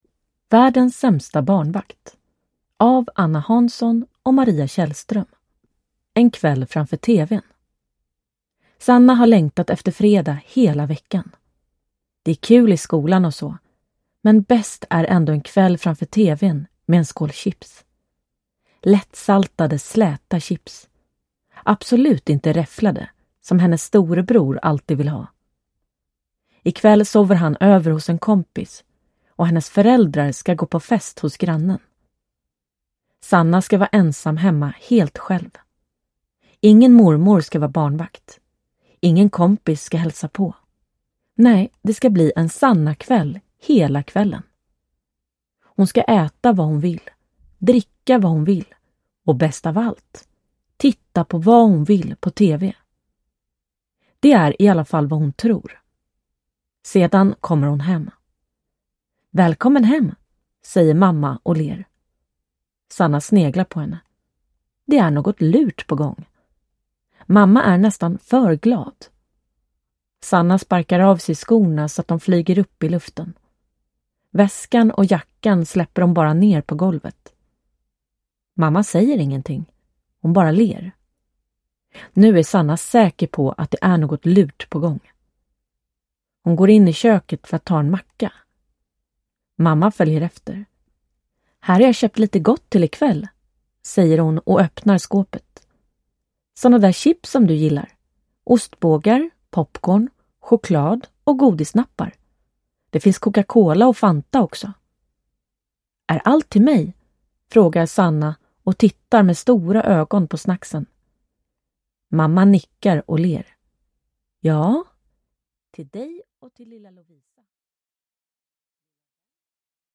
Världens sämsta barnvakt – Ljudbok – Laddas ner